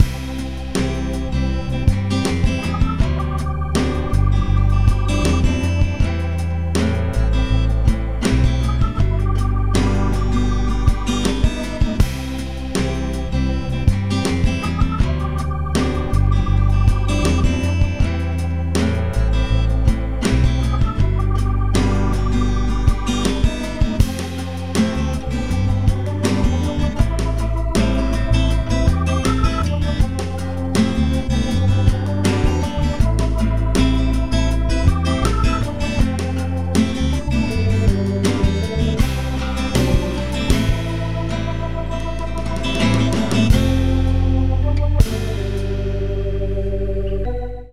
(0:47) Some melancolic pop/folk love music